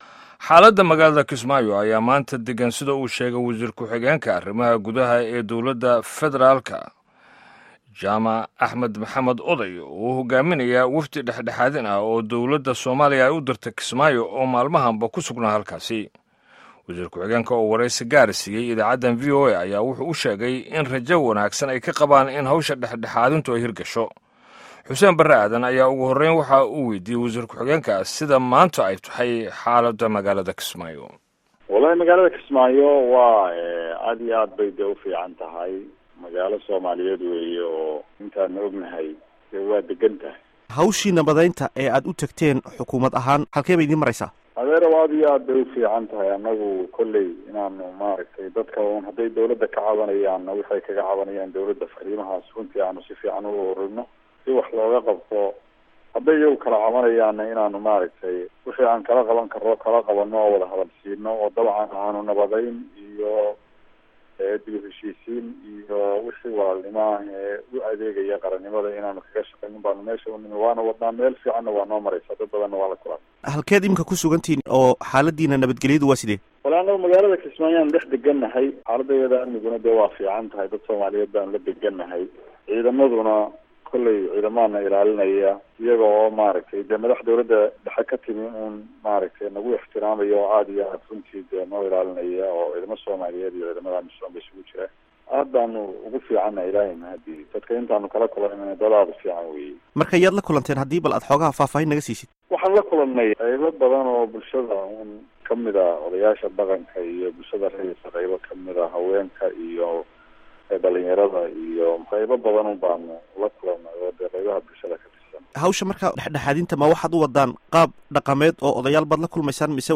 Wareysiga Jaamac Oday